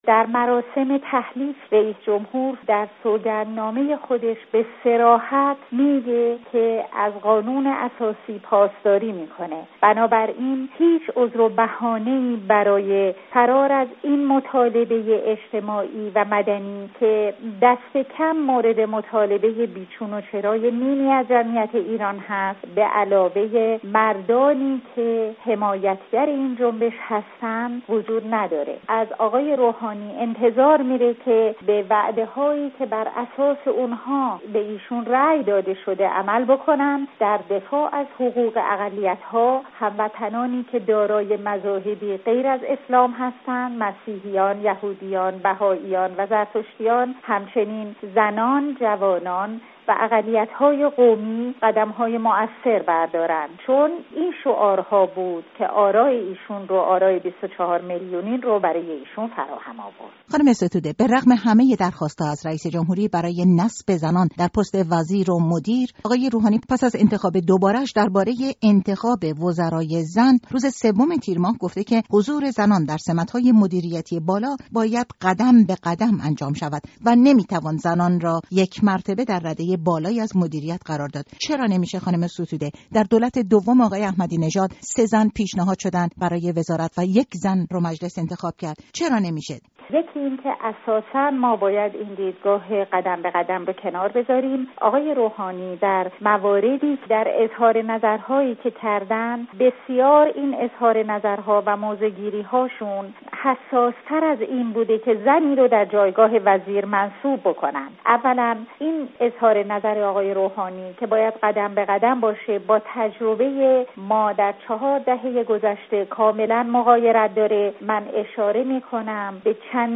گفت‌وگو با نسرین ستوده، فعال حقوق زنان، درباره درخواست‌ها برای انتخاب وزیران زن در کابینه آینده